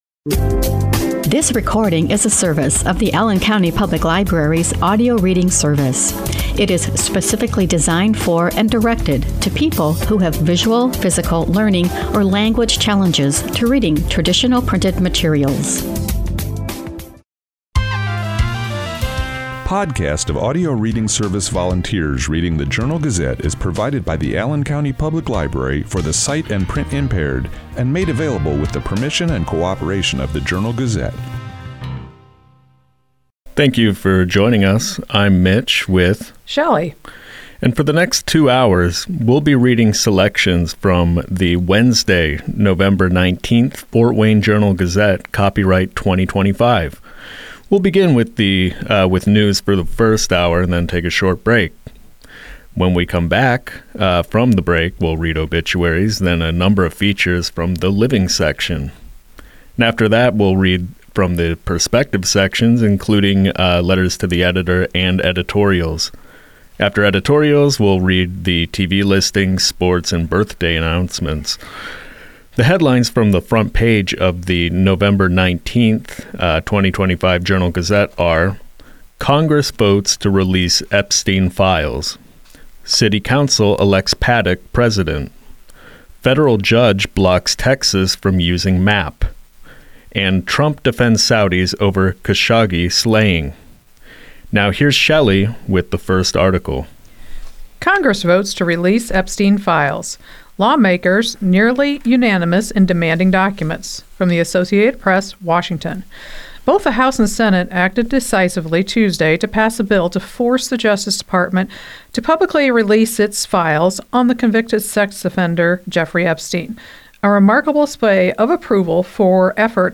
Audio Reading Service Recordings